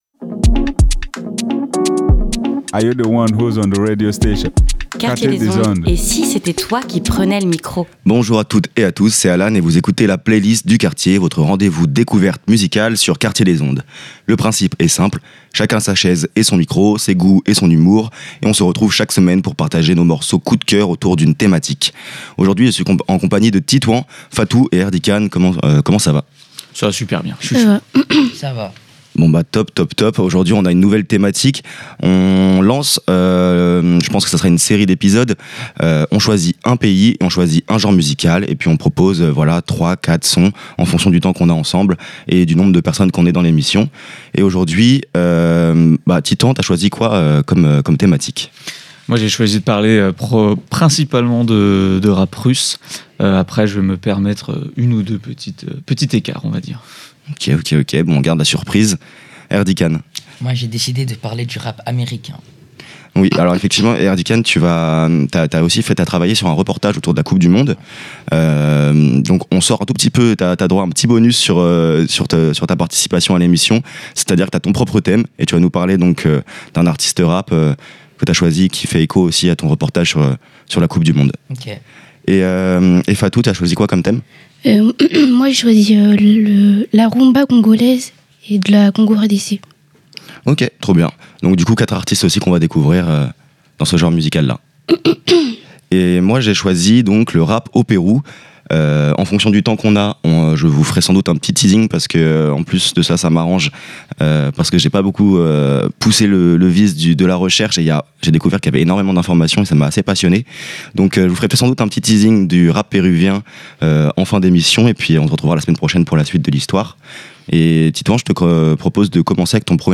La playlist du quartier, votre rendez-vous musical, chill & relax sur Quartier des Ondes.